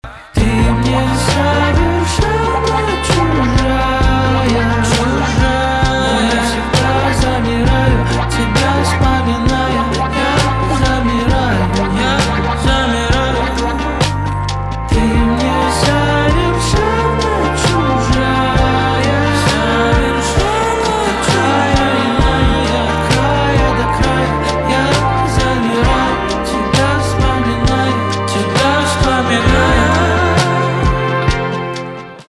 инди
грустные , печальные
гитара , барабаны , чувственные